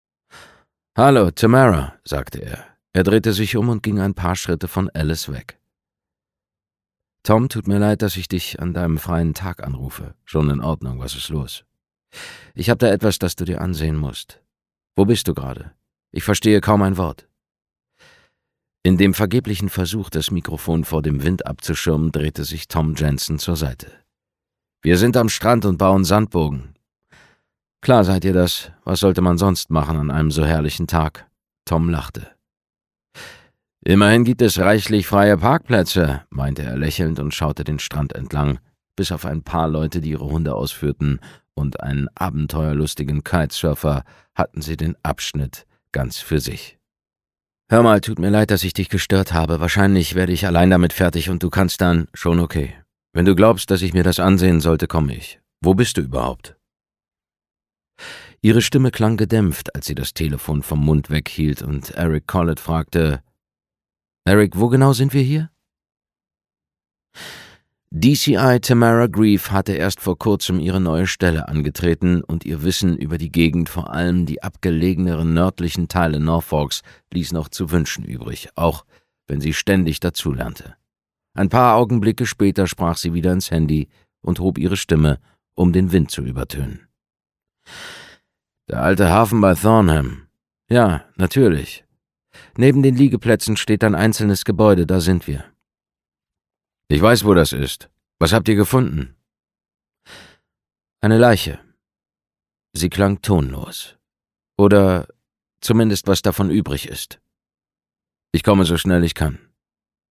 Infos zum Hörbuch